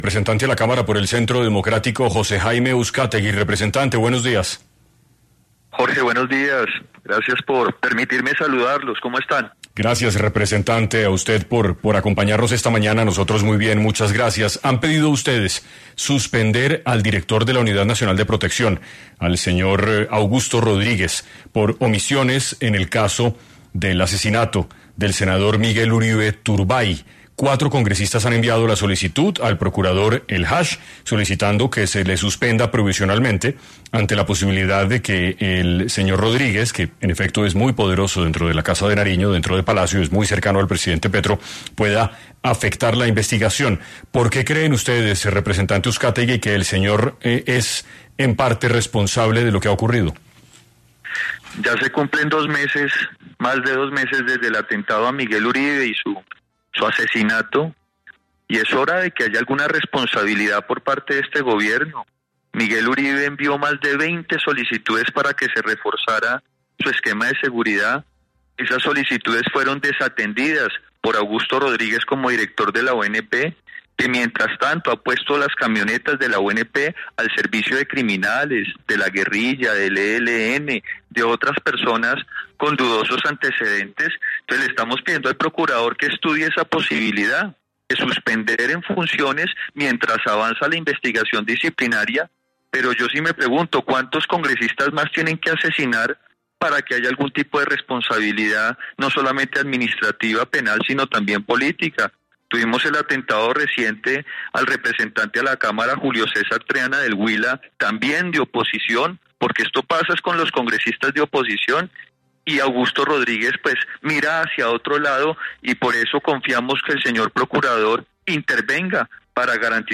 El representante, José Uscátegui habló en 6AM sobre cuáles serían las omisiones que el director de la Unidad Nacional de Protección habría cometido con Miguel Uribe